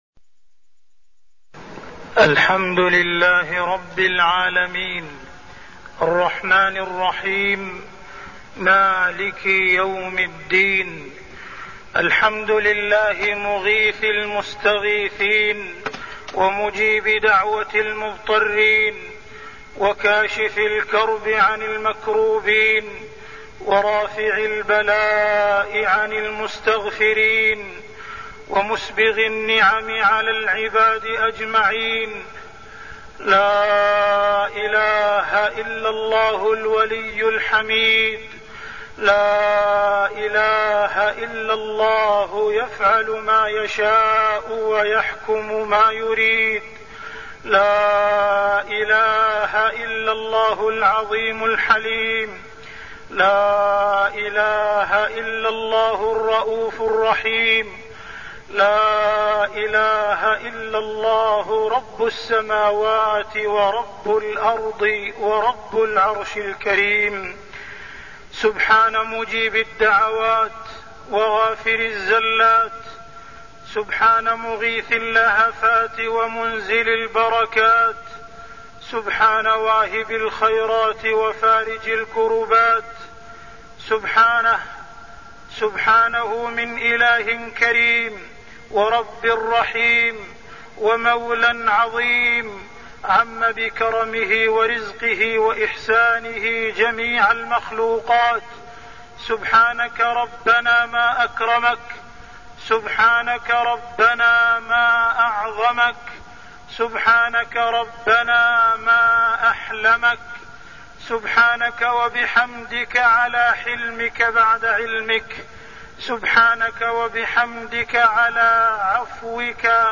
تاريخ النشر ١٧ جمادى الآخرة ١٤١٥ هـ المكان: المسجد الحرام الشيخ: معالي الشيخ أ.د. عبدالرحمن بن عبدالعزيز السديس معالي الشيخ أ.د. عبدالرحمن بن عبدالعزيز السديس غيث القلوب وغيث الأرض